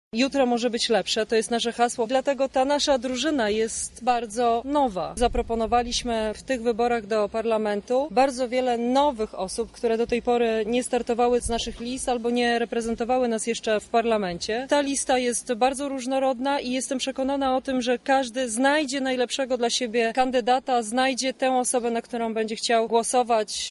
O tym co jest głównym wspólnym celem Koalicji Obywatelskiej mówi posłanka Joanna Mucha, numer jeden na liście w okręgu 6 obejmującym Lublin: